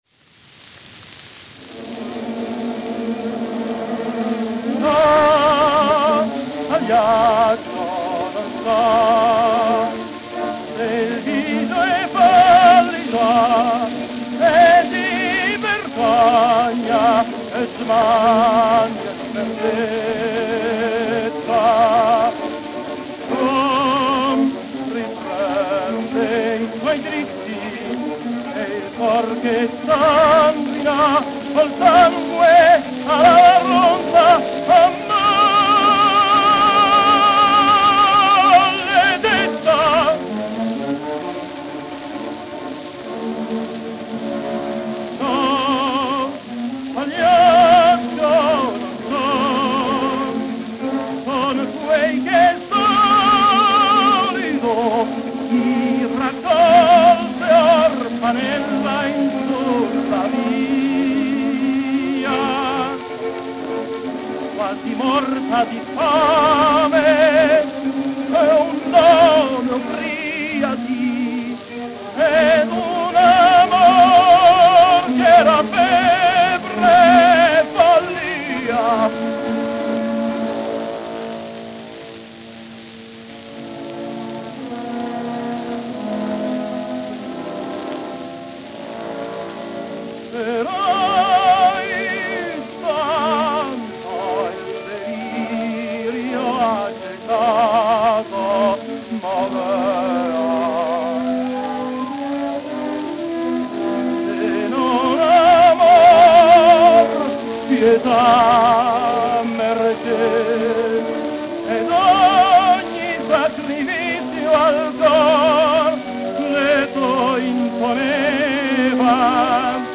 Tenor Solo